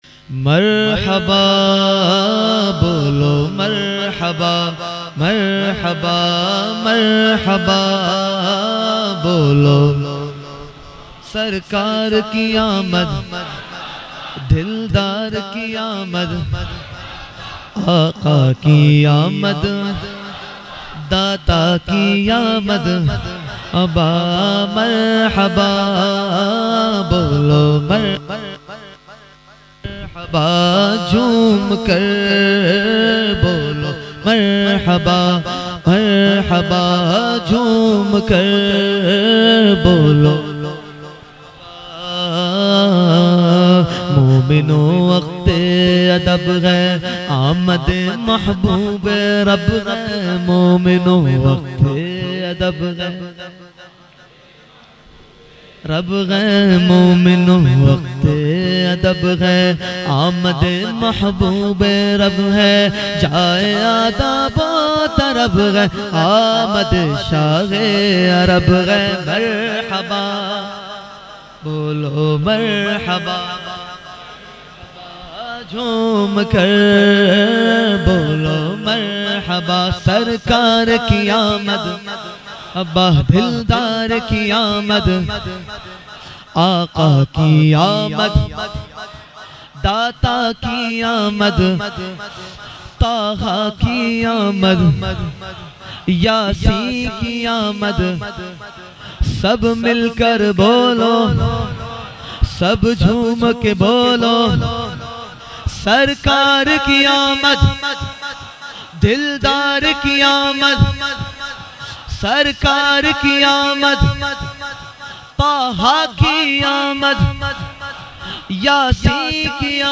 Mehfil e Jashne Subhe Baharan held on 28 September 2023 at Dargah Alia Ashrafia Ashrafabad Firdous Colony Gulbahar Karachi.
Category : Naat | Language : UrduEvent : Jashne Subah Baharan 2023